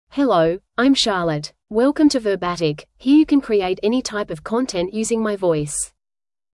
Charlotte — Female English (Australia) AI Voice | TTS, Voice Cloning & Video | Verbatik AI
FemaleEnglish (Australia)
Charlotte is a female AI voice for English (Australia).
Voice sample
Charlotte delivers clear pronunciation with authentic Australia English intonation, making your content sound professionally produced.